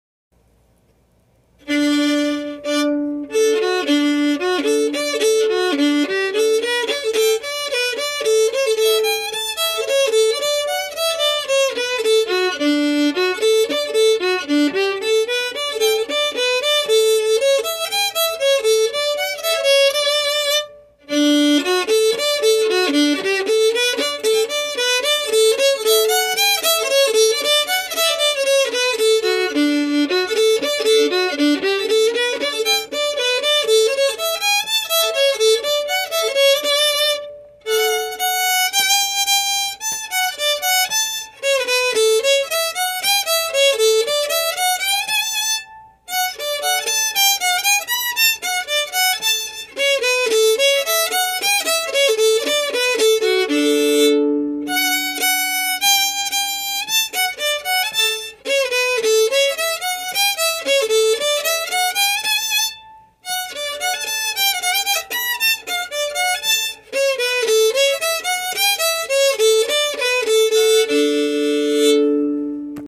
Key: D
Form: Quickstep or Polka
Played slowly for learning
M: 2/4
Genre/Style: Old-Time